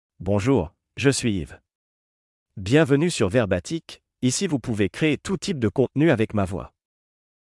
YvesMale French AI voice
Yves is a male AI voice for French (France).
Voice sample
Male
Yves delivers clear pronunciation with authentic France French intonation, making your content sound professionally produced.